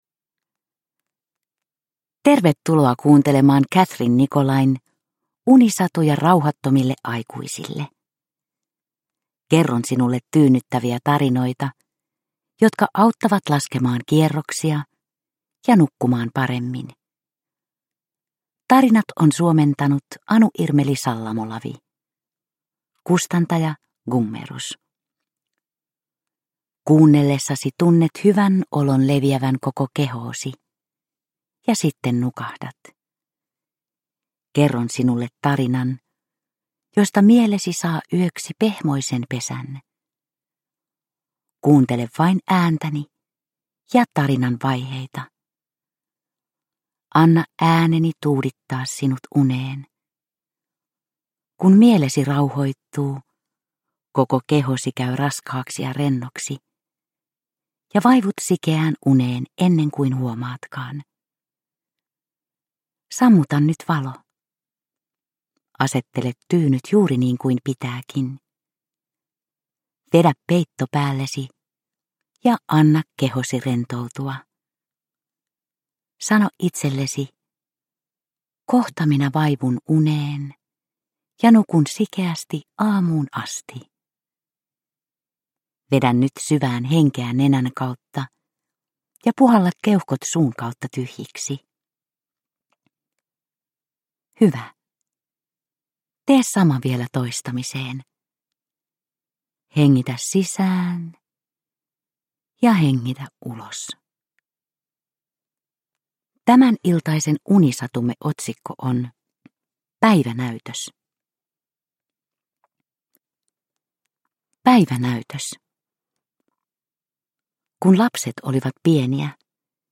Unisatuja rauhattomille aikuisille 22 - Päivänäytös – Ljudbok – Laddas ner